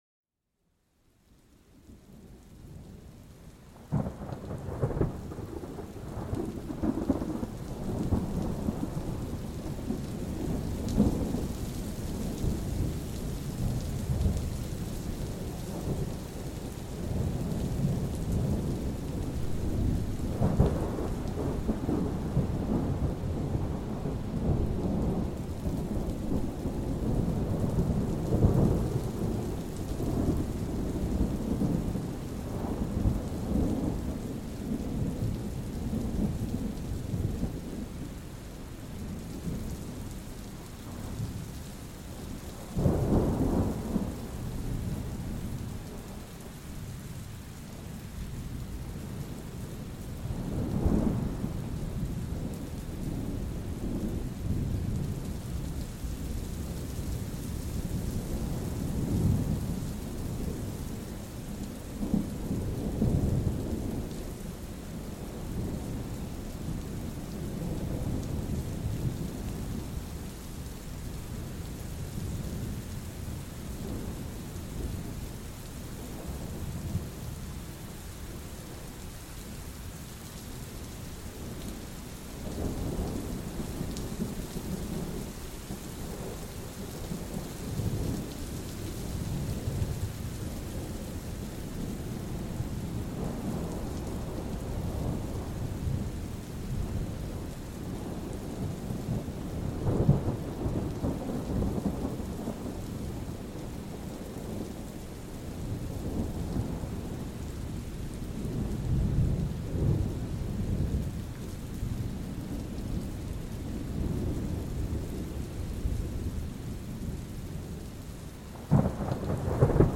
⛈ Armonía Tormentosa: La Melodía Relajante de la Lluvia y el Trueno
Explora la esencia cautivadora de una tormenta, donde el susurro de la lluvia y el lejano rugido del trueno se mezclan en una armonía natural. Vive una inmersión auditiva que transforma el poder de la tormenta en una melodía relajante, propicia para la relajación y el sueño.